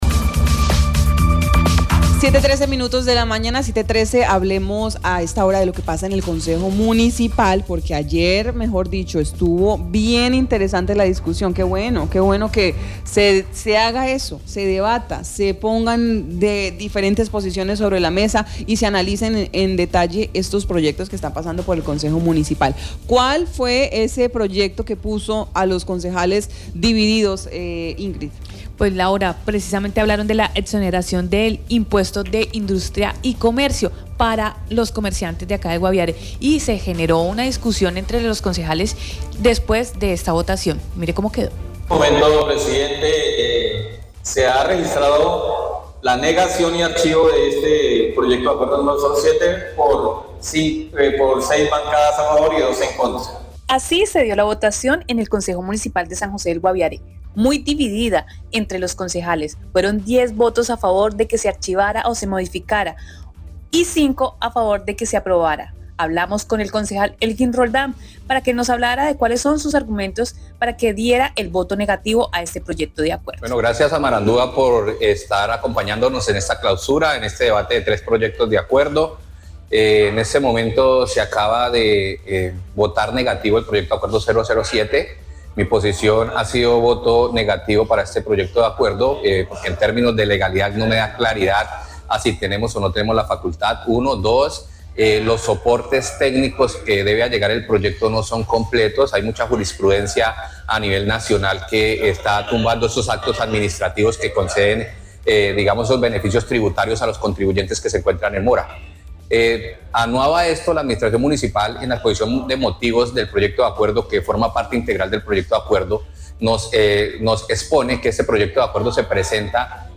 Al clausurar el tercer periodo de sesiones ordinarias en el Concejo Municipal de San José del Guaviare, se registró una fuerte discusión en torno a dos proyectos uno sobre incentivos tributarios que buscaba darle algunos beneficios a comerciantes como la exoneración del impuesto de Industria y Comercio y otro sobre traslados presupuestales, se escucharon voces a favor y en contra, pero finalmente el proyecto de incentivos tributarios se hundió.